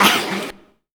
Index of /90_sSampleCDs/Zero-G - Total Drum Bass/Instruments - 3/track61 (Vox EFX)
04-Erher.wav